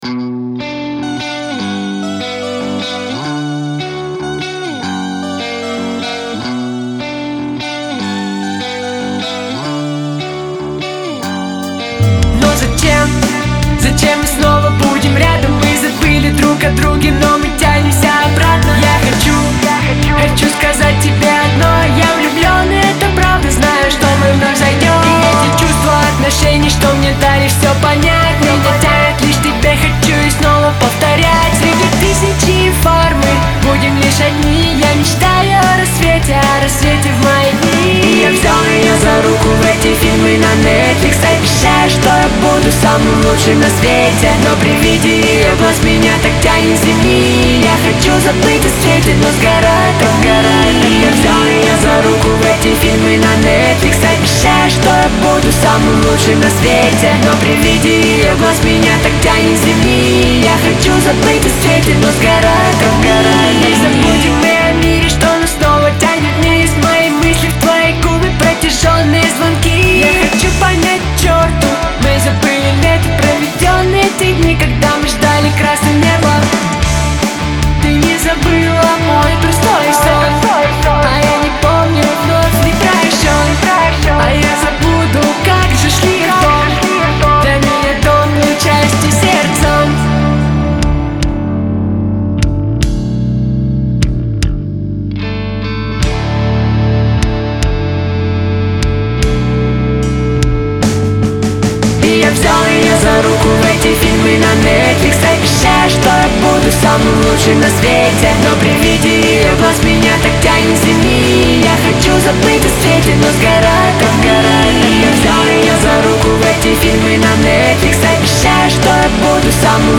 это мощный трек в жанре альтернативного рока